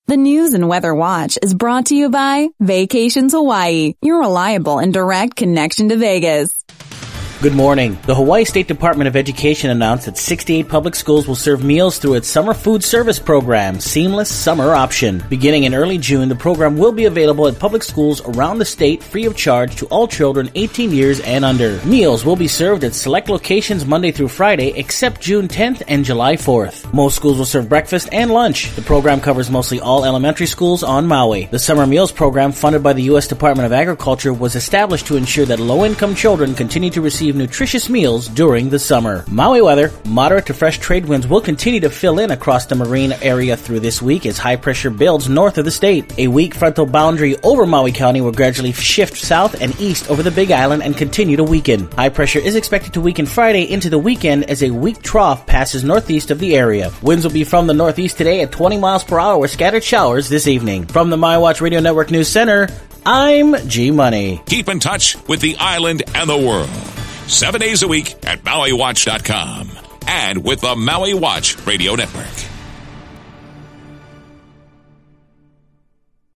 Your daily news & weather brief for May 31